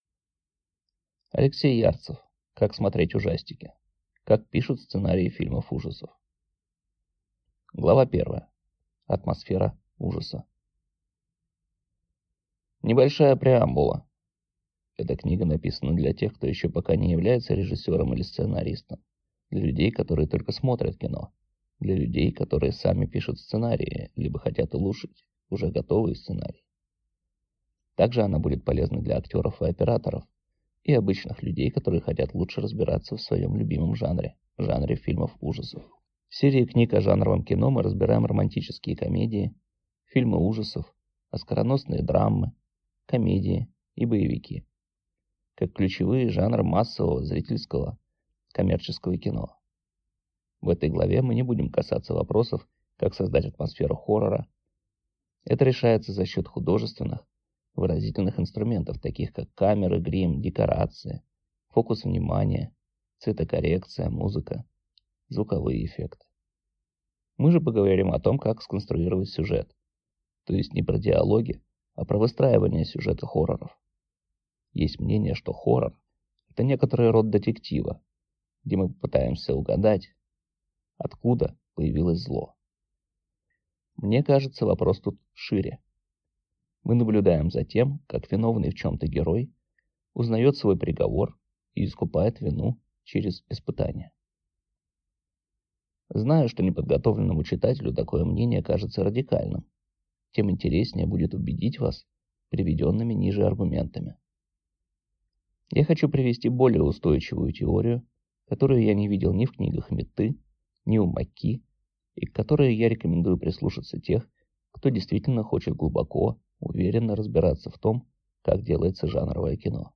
Аудиокнига Как смотреть ужастики. Как пишут сценарии фильмов ужасов | Библиотека аудиокниг